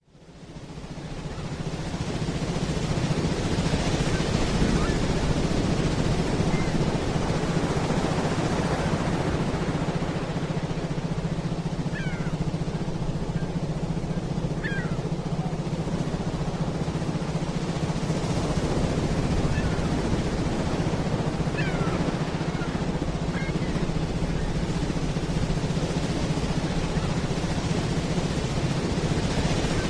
Contains Binuaral Beat. Headphones recommended. Listen while studying or memorizing information.